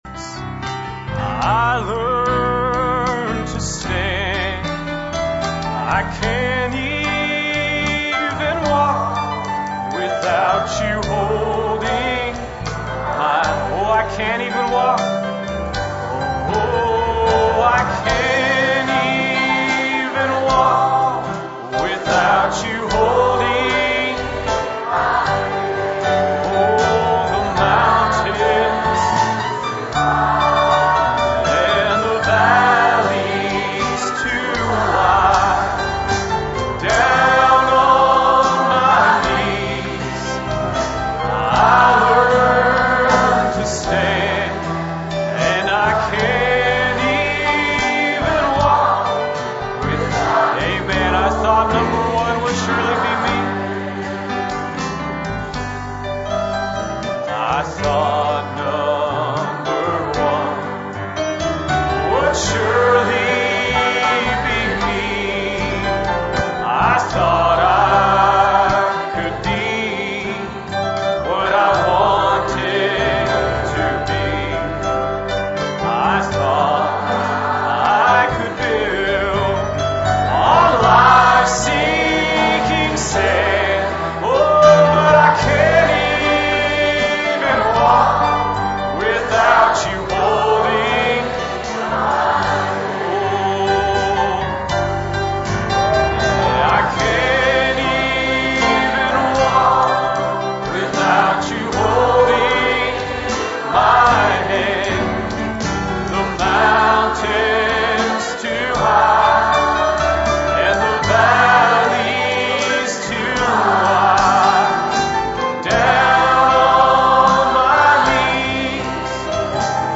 For this or any other sermon on DVD, please contact the library using the contact form on the website.